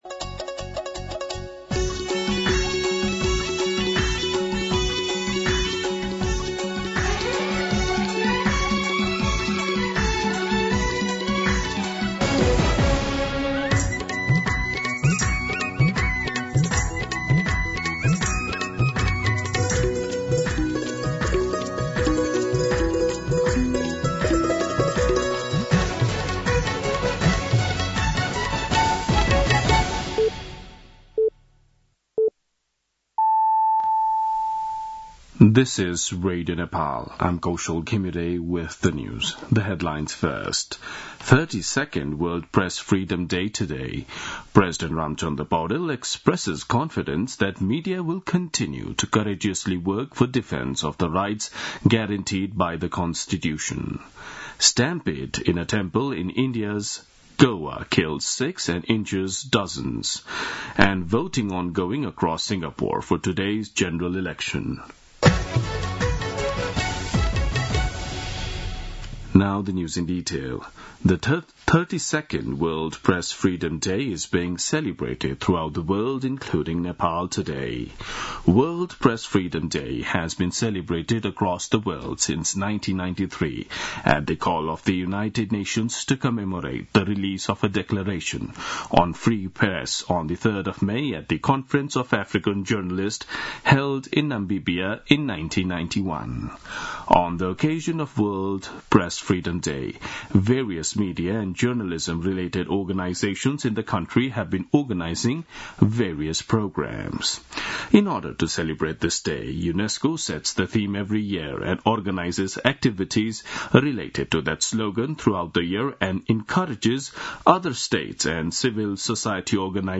दिउँसो २ बजेको अङ्ग्रेजी समाचार : २० वैशाख , २०८२
2pm-English-News-01-20.mp3